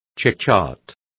Complete with pronunciation of the translation of chitchat.